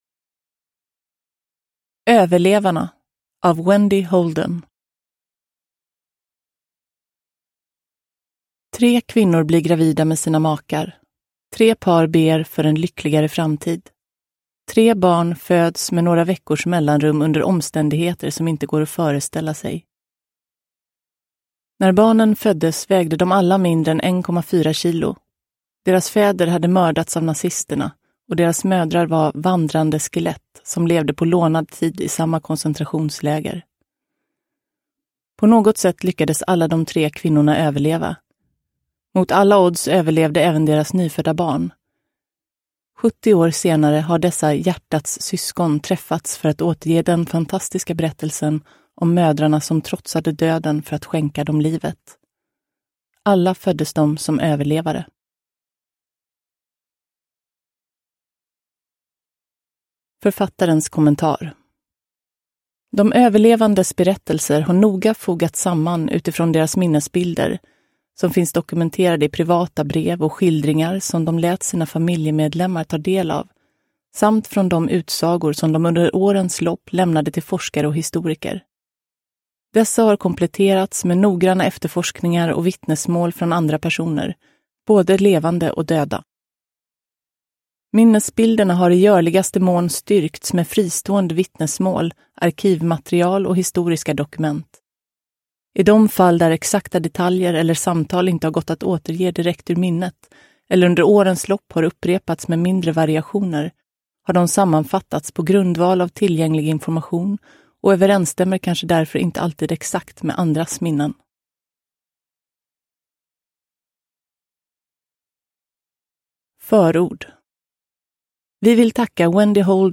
Överlevarna : tre unga mödrar och deras otroliga historia om mod och överlevnad – Ljudbok – Laddas ner